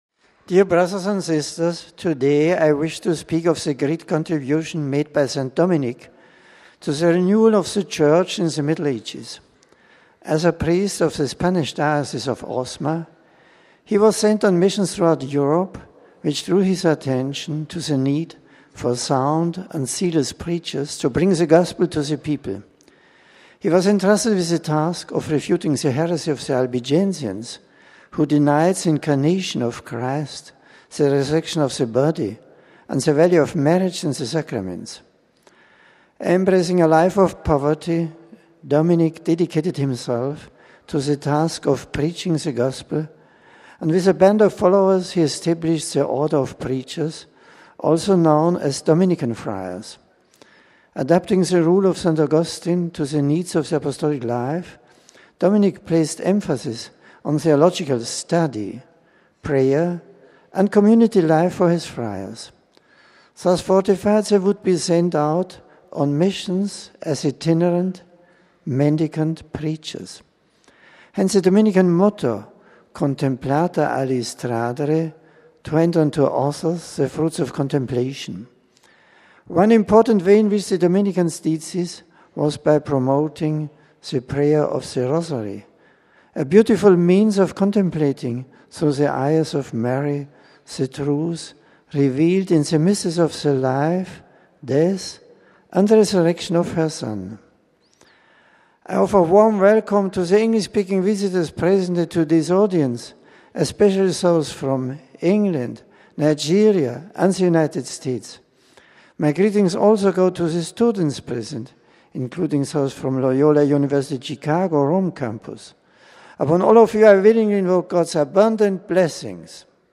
The General Audience of Feb. 3rd was held in the Vatican’s Paul VI Audience Hall. It began with aides taking turns reading a scripture passage in various languages. One of the aides greeted the Pope on behalf of the English-speaking pilgrims and presented the various groups to him. Pope Benedict then delivered a reflection in English.